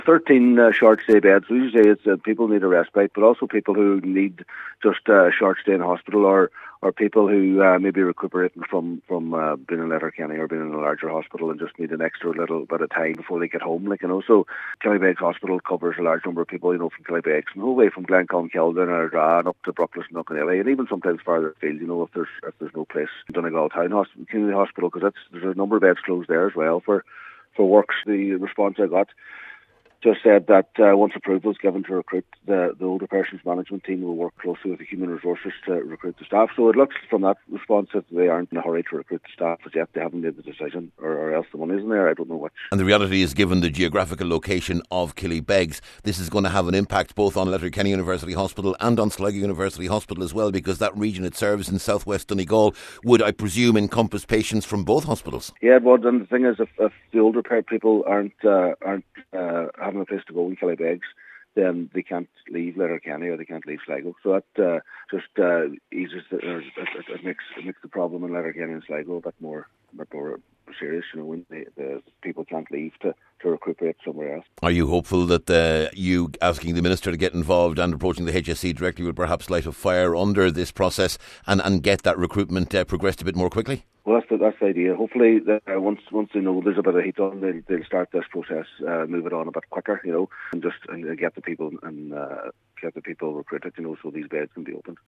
Cllr Brogan is calling for more urgency on the matter..…….